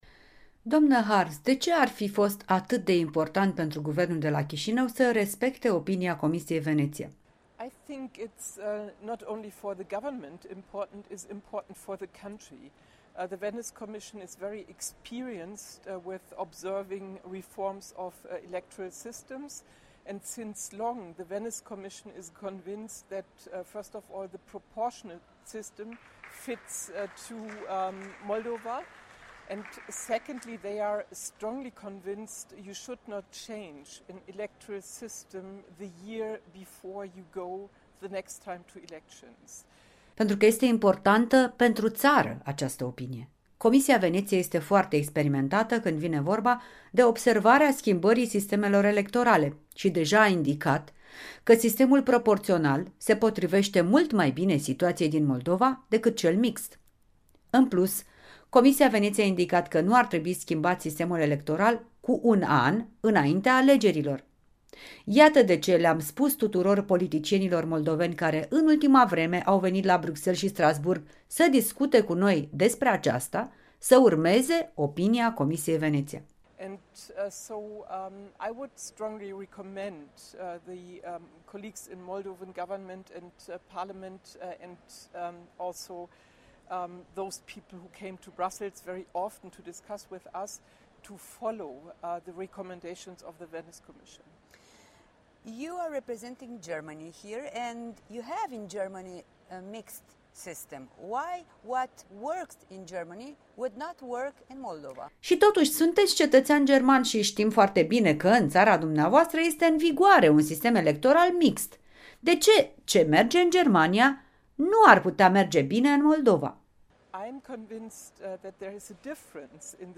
Eurodeputata Rebecca Harms intervievată la Strasbourg